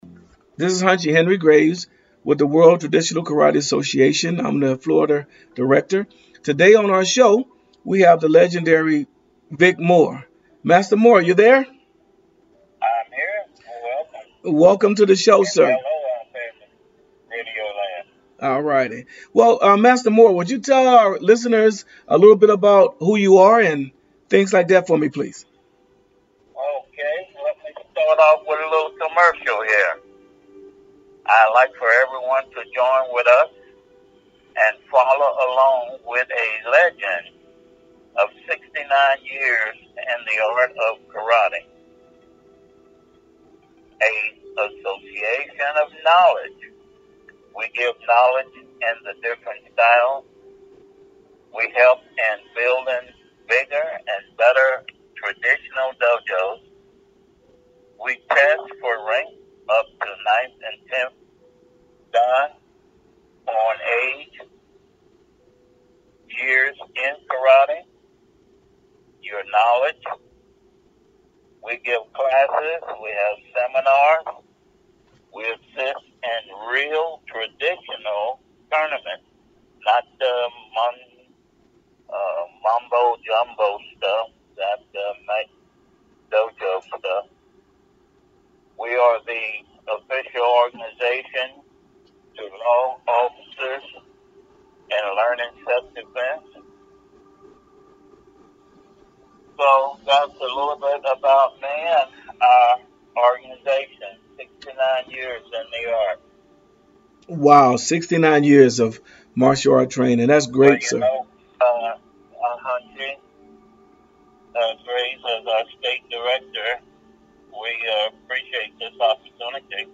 The Way: Vic Moore interview